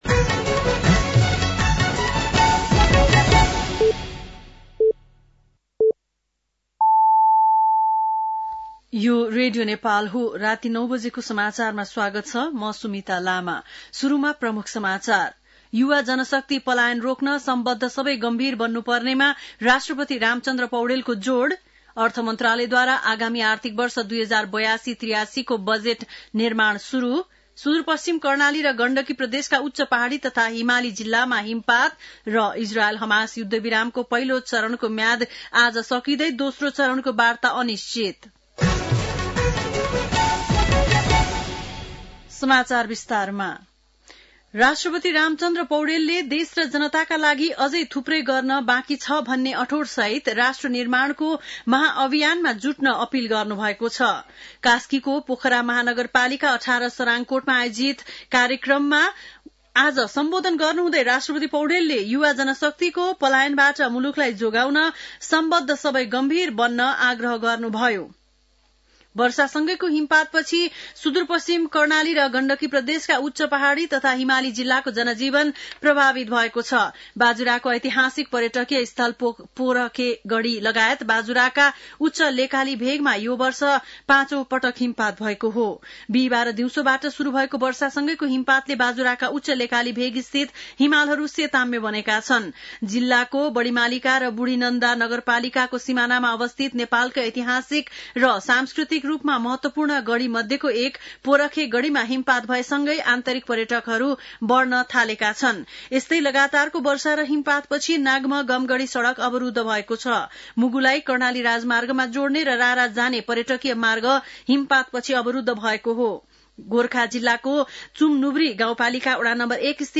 बेलुकी ९ बजेको नेपाली समाचार : १८ फागुन , २०८१